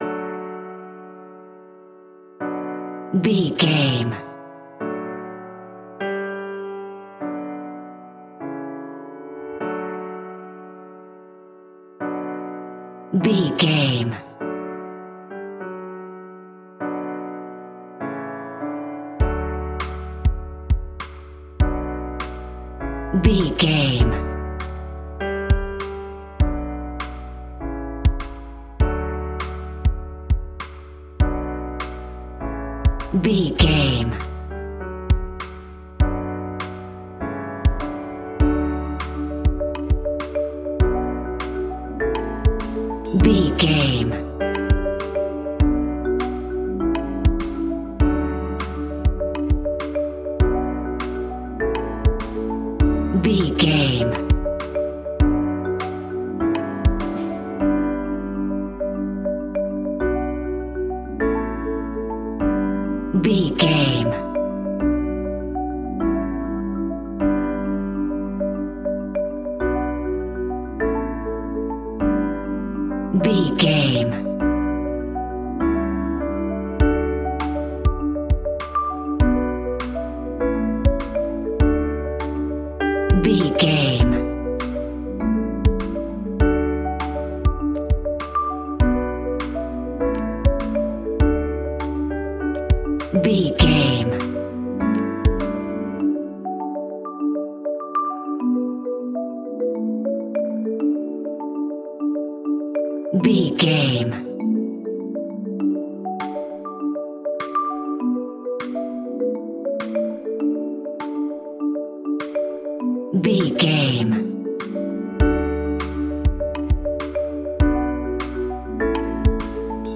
A great piece of royalty free music
Ionian/Major
D
pop
pop rock
indie pop
fun
energetic
uplifting
cheesy
upbeat
groovy
guitars
bass
drums
organ